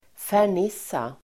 Uttal: [fär_n'is:a]